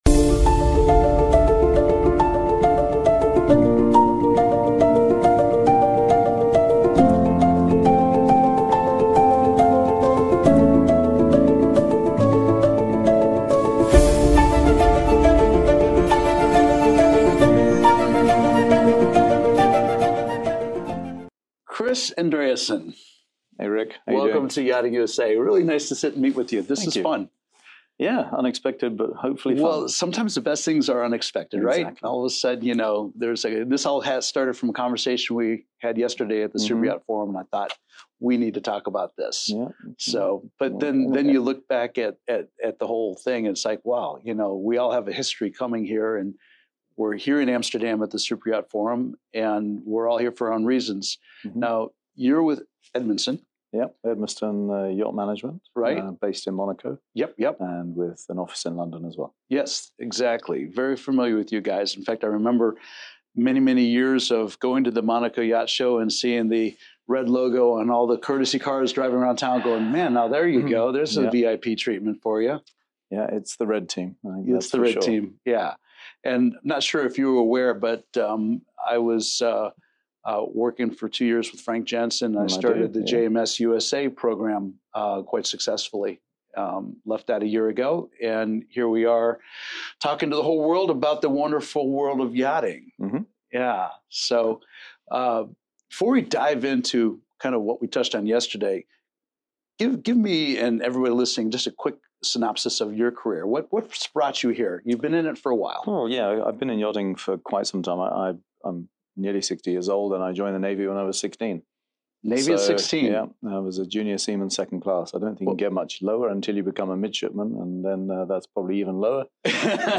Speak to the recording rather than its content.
recorded at METSTRADE in Amsterdam